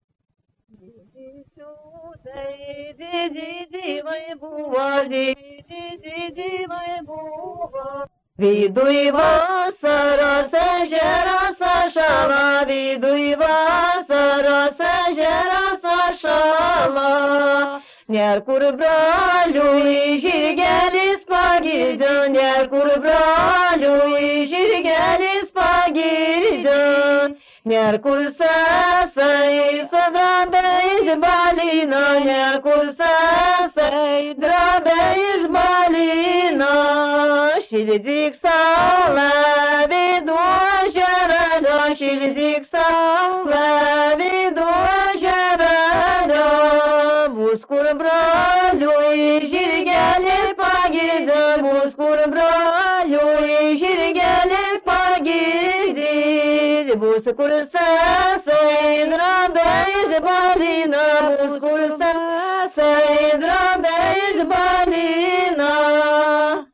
The songs are usually performed in a unique "shouting" singing style.
Most Shrovetide songs are recitative-like and their melodies contain the most archaic ritual melodic characteristics.